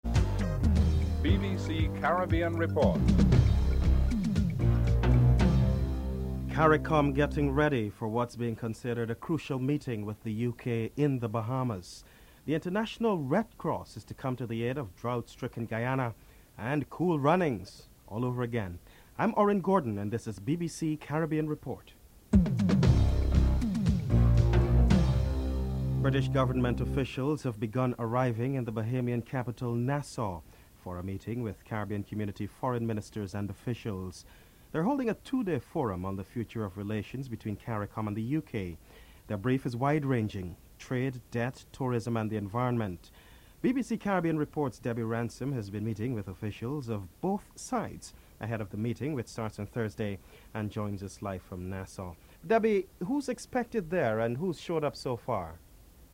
3. CARICOM Secretary-General Edwin Carrington hopes that CARICOM and the UK can establish a joint position on the EU's proposal to split up the ACP Group (03:13-05:29)
9. Recap of top stories (14:28-15:09)